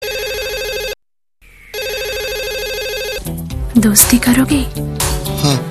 короткие
индийские
Классный бодрый рингтон на любой случай